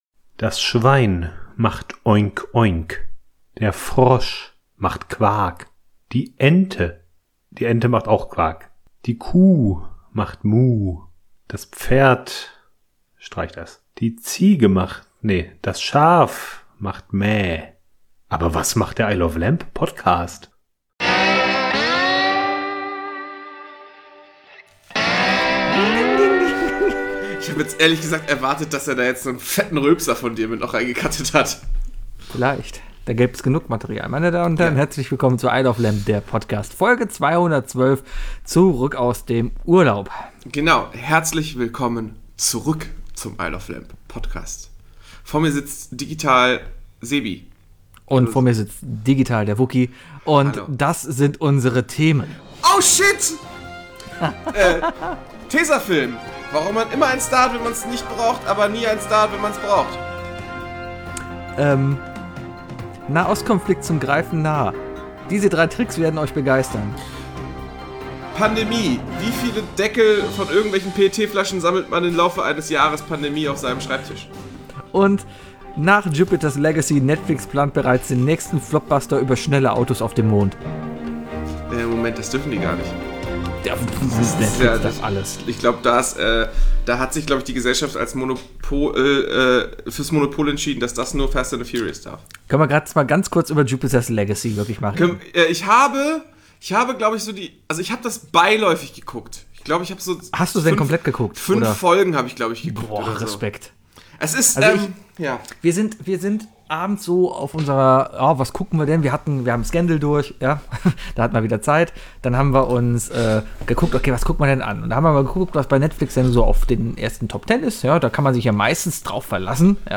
Ich weiss auch nicht was los ist aber wir singen heute irgendwie viel.